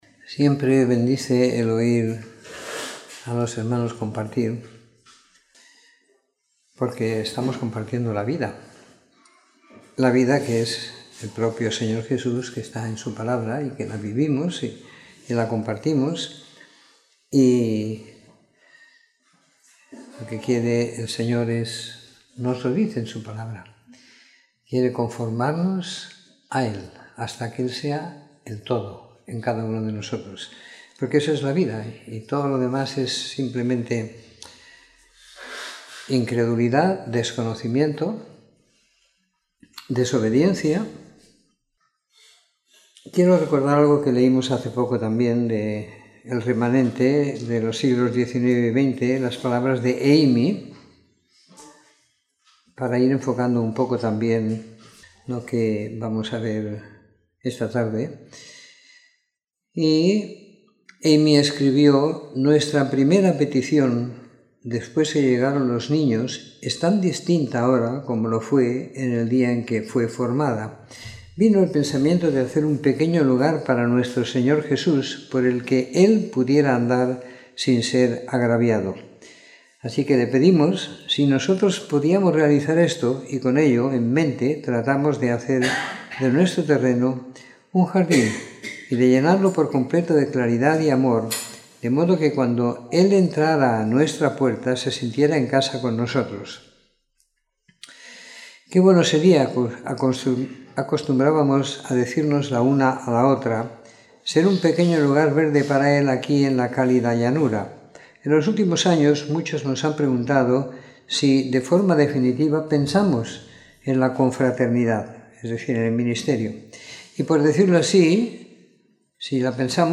Domingo por la Tarde . 13 de Mayo de 2018